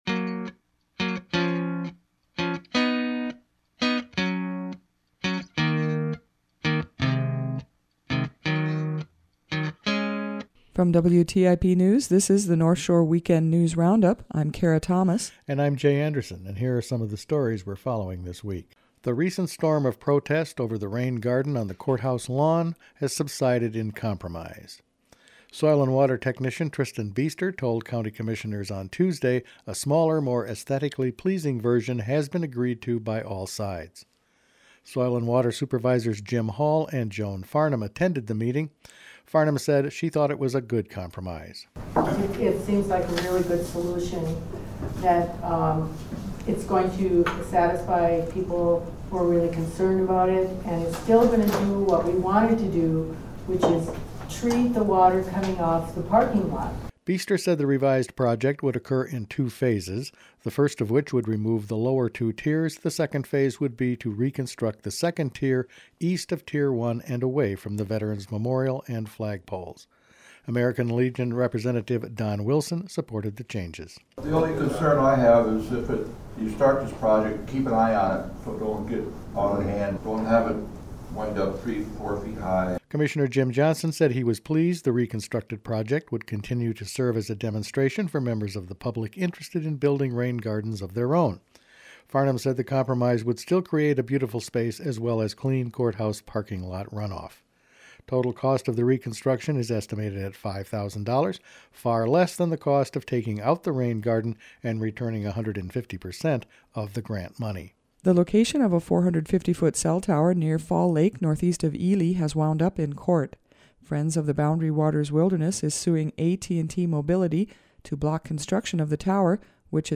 Each week the WTIP News Department provides a summary of the stories it has been following that week.